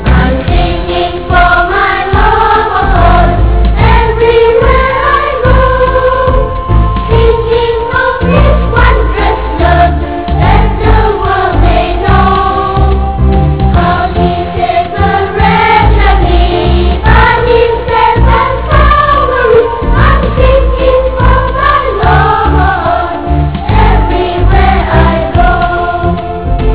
as a platform for the children to learn to sing hymns